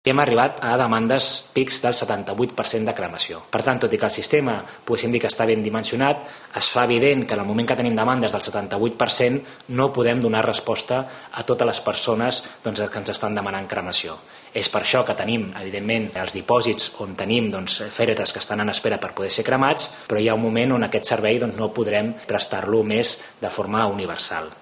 Audio de Eloi Badia